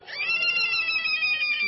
rabbit_injured.mp3